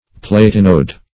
Platinode \Plat"i*node\, n.